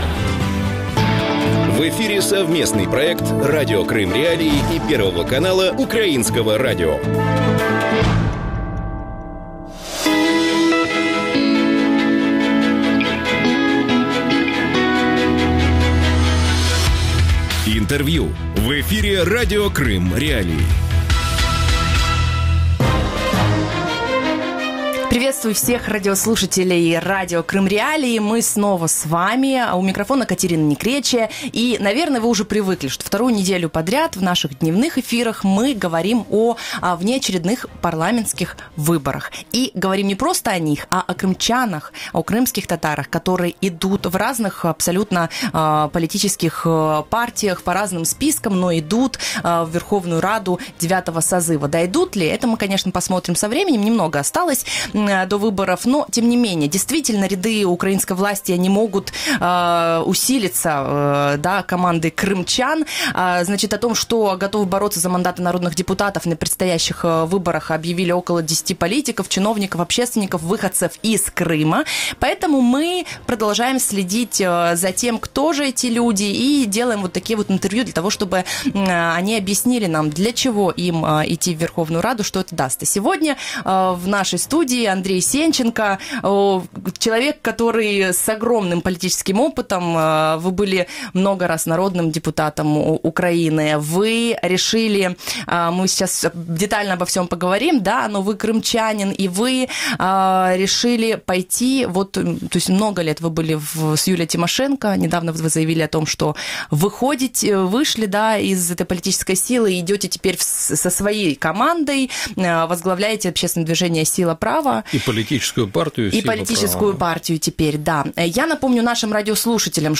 Гость студии: Андрей Сенченко, председатель общественной организации «Сила права» и глава одноименной партии, экс-вице-премьер автономной Республики Крым.